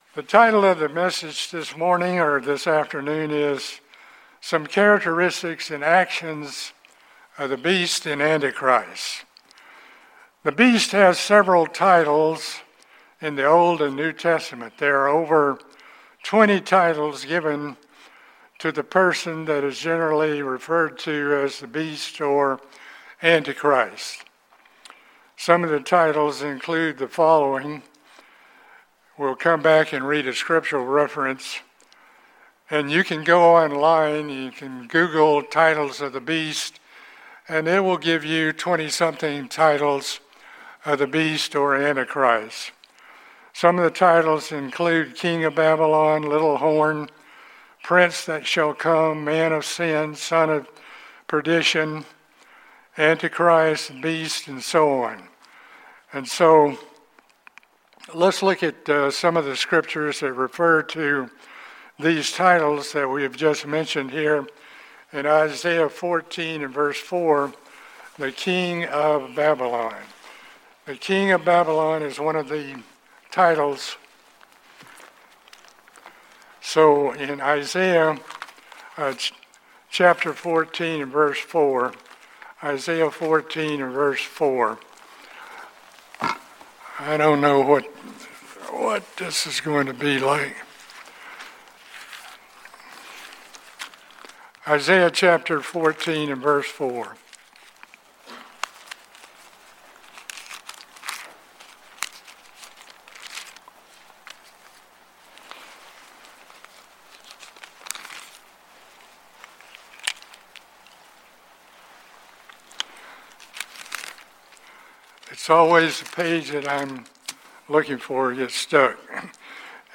This sermon explores some characteristics and actions of the beast. This includes how the beast comes to power and the great deceptive power of Satan.